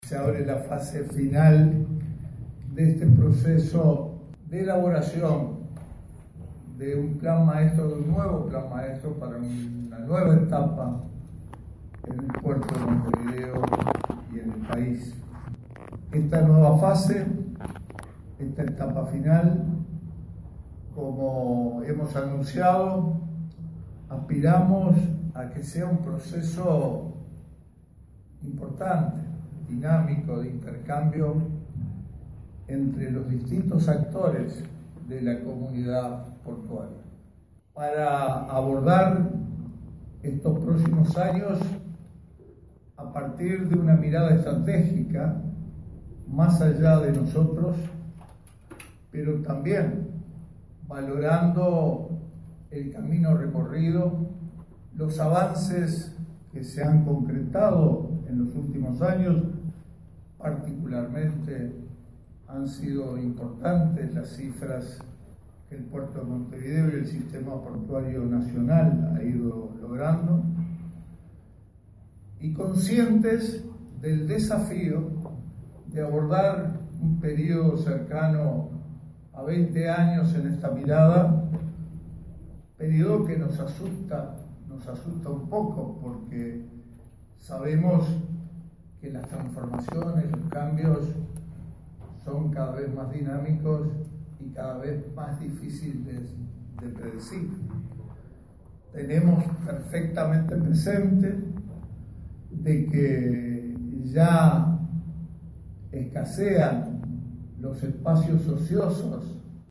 Se llevó a cabo un evento del lanzamiento del Plan Maestro de la Administración de Puertos 2018. Contó con la participación del ministro de Transporte y Obras Públicas, Victor Rossi, y el titular de los Puertos, Alberto Díaz, además de otras autoridades.